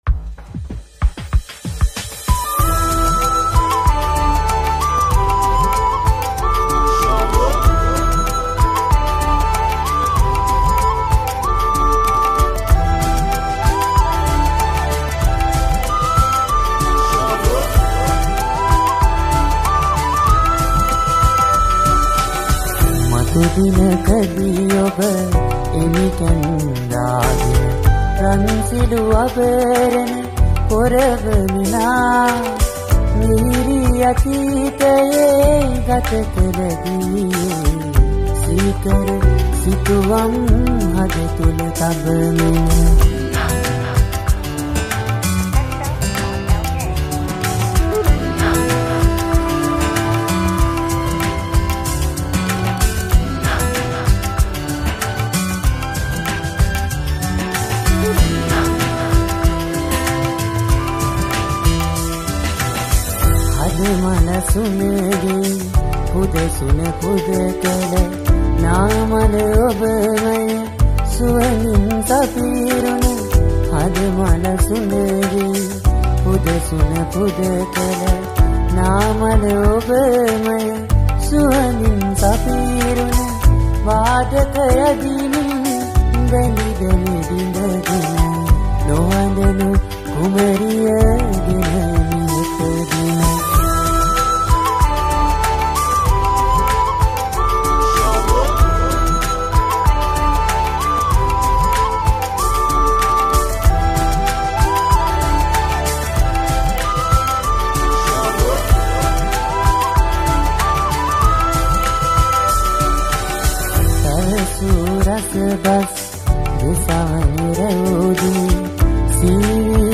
High quality Sri Lankan remix MP3 (3).